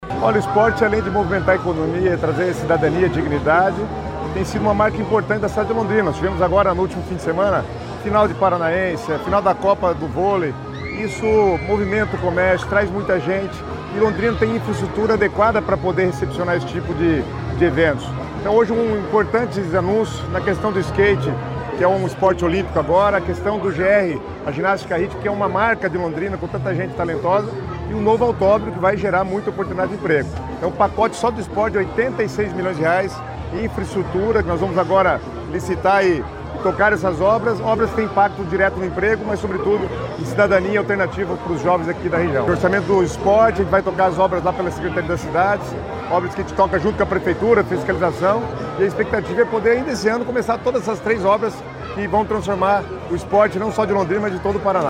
Sonora do secretário das Cidades, Guto Silva, sobre os anúncios feitos para a área esportiva em Londrina